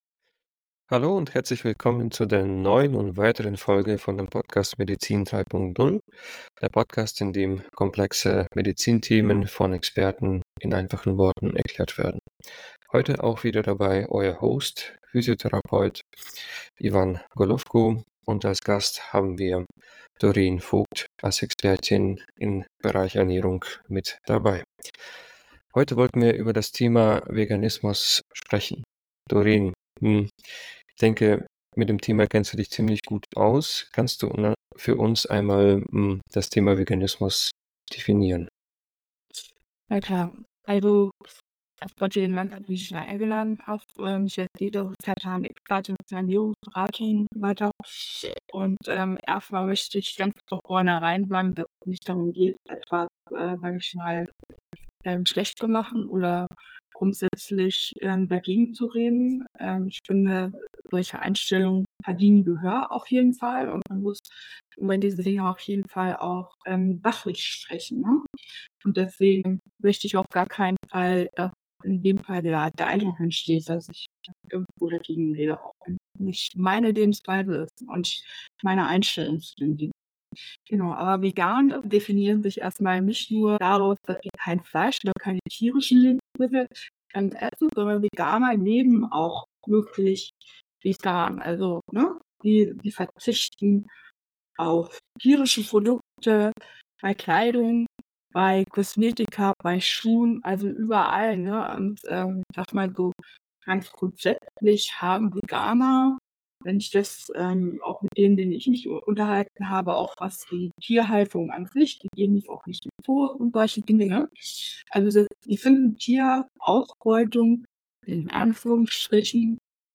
In diesem Teil des Gesprächs geht es um die Erkennung von Nahrungsmittelunverträglichkeiten, insbesondere Glutenunverträglichkeit.